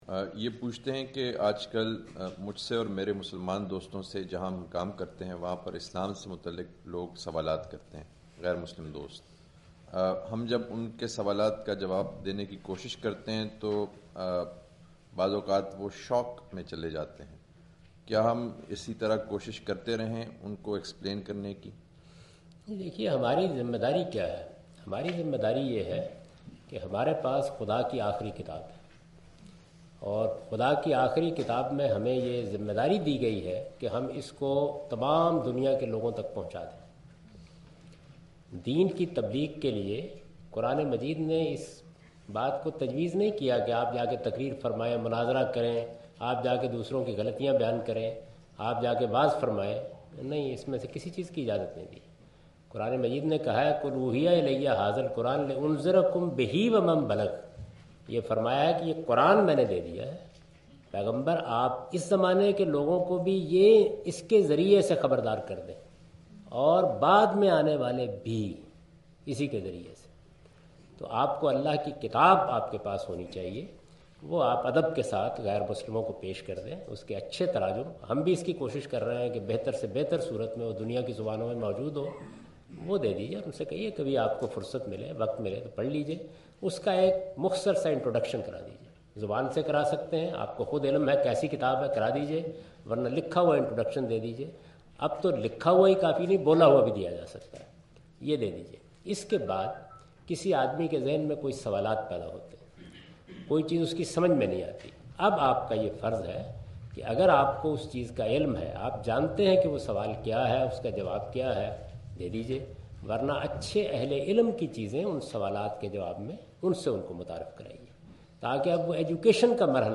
Javed Ahmad Ghamidi answer the question about "how to preach Islam?" during his visit to Queen Mary University of London UK in March 13, 2016.
جاوید احمد صاحب غامدی اپنے دورہ برطانیہ 2016 کےدوران کوئین میری یونیورسٹی اف لندن میں "تبلیغ کا طریقہ" سے متعلق ایک سوال کا جواب دے رہے ہیں۔